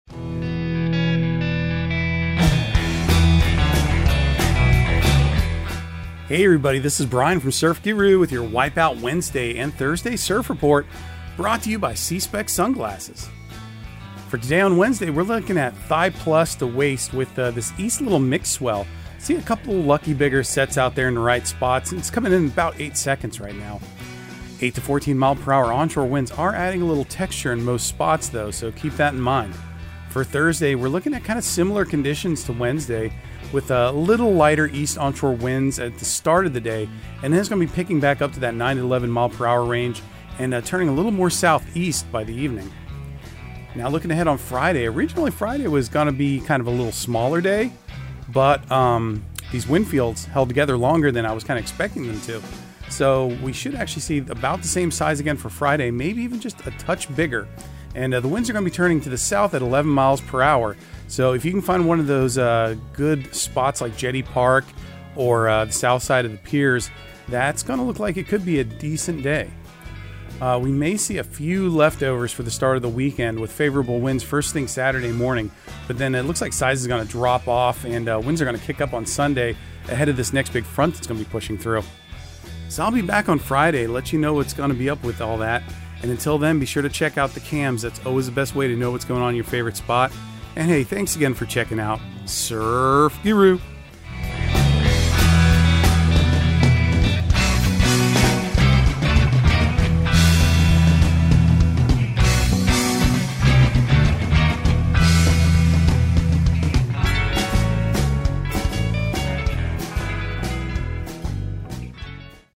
Surf Guru Surf Report and Forecast 04/26/2023 Audio surf report and surf forecast on April 26 for Central Florida and the Southeast.